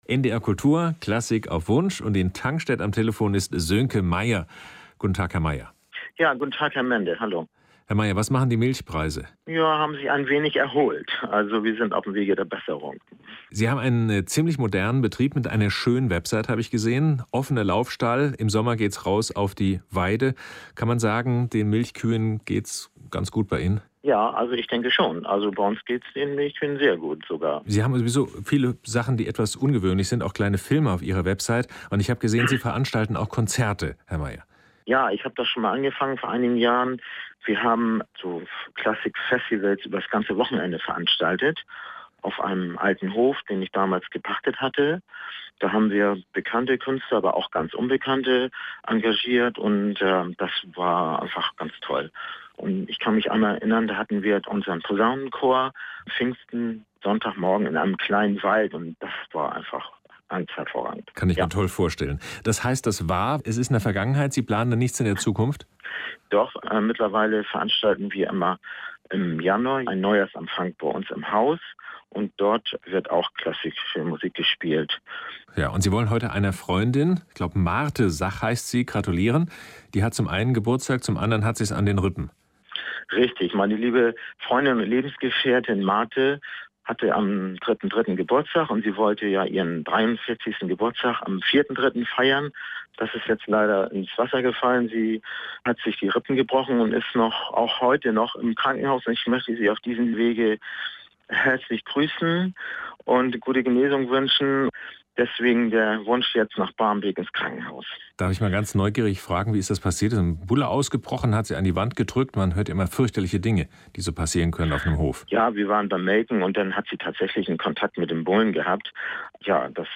Unsere Hofband Duo per tutti
Querflöte
Klavier
hofband.mp3